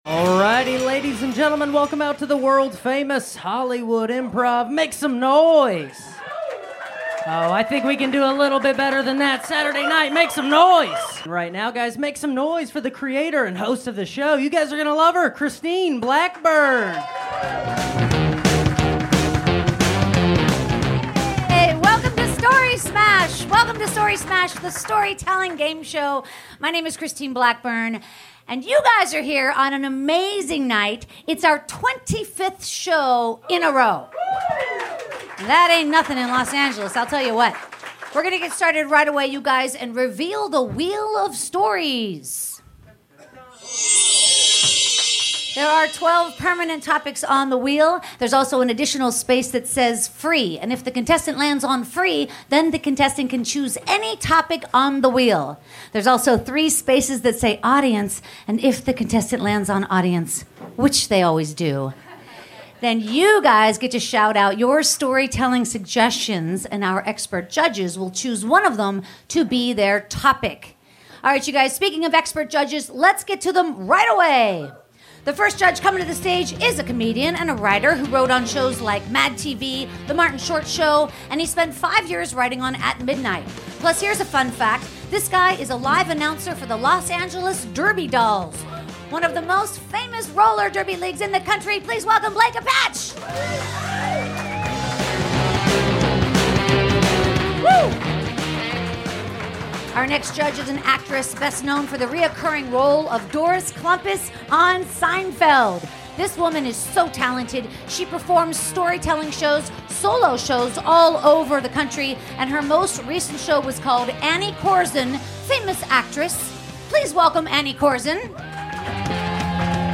578 - Story Smash the Storytelling Gameshow LIVE at The Hollywood Improv!